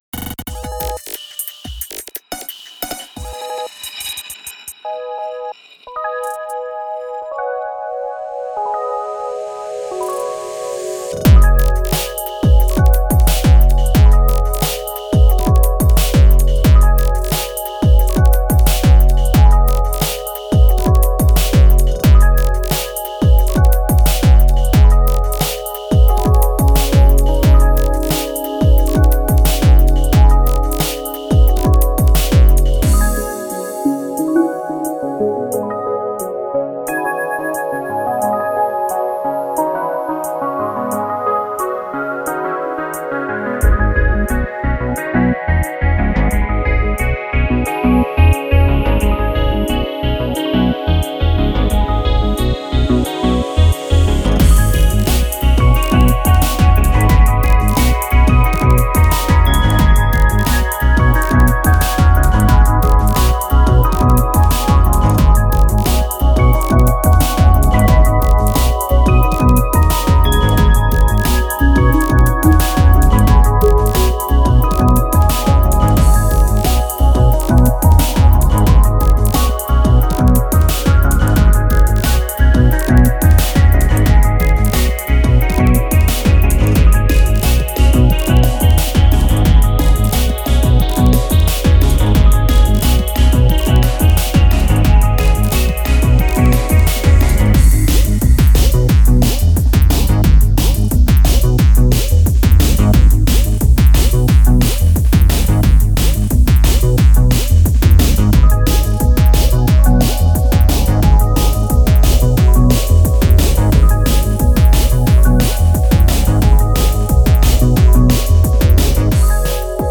a squishy little electro breaks track